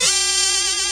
ZWEEE.wav